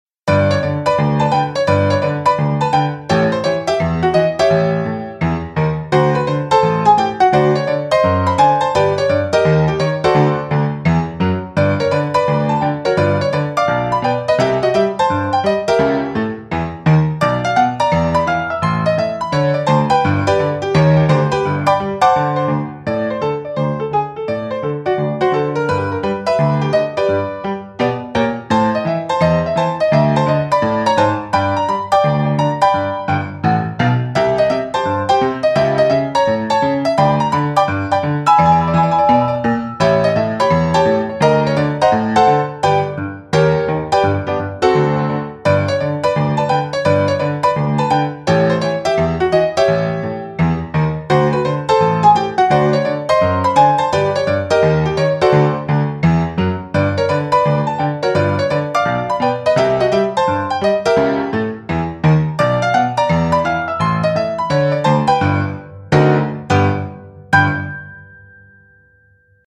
Genres:ComicTiktok-Memes